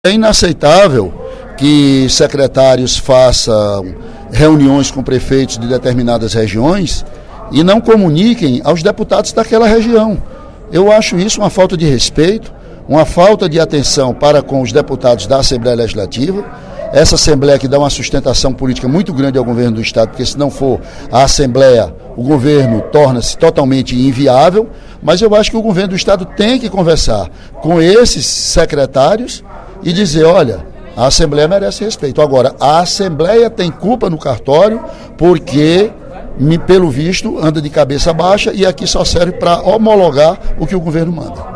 O deputado Ely Aguiar (PSDC) criticou, durante pronunciamento no primeiro expediente da sessão plenária desta quarta-feira (05/12), o tratamento dado por alguns secretários do Estado aos parlamentares da Casa.